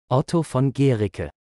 Otto von Guericke (UK: /ˈɡɛərɪkə/ GAIR-ik-ə,[1] US: /ˈɡ(w)ɛərɪkə, -ki/ G(W)AIR-ik-ə, -ee,[2] German: [ˈɔtoː fɔn ˈɡeːʁɪkə]
Otto_von_Guericke_Prononciation.ogg.mp3